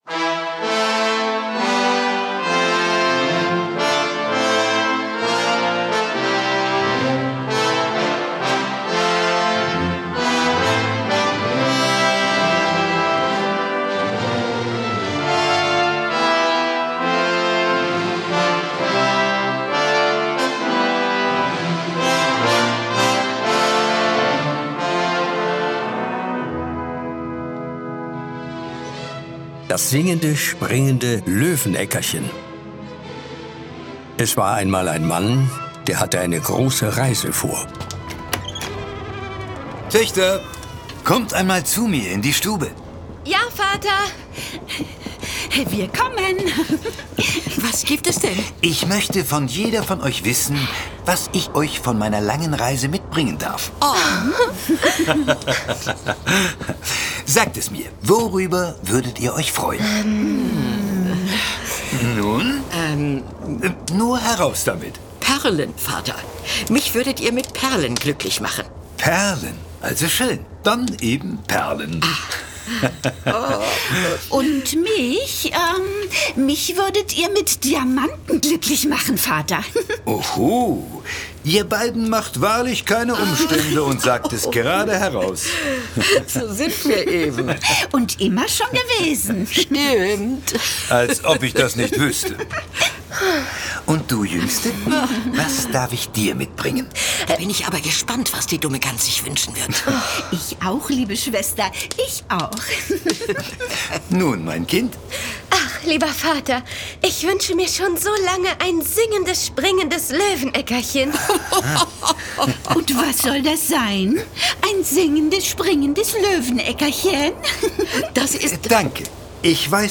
Drei Hörspiele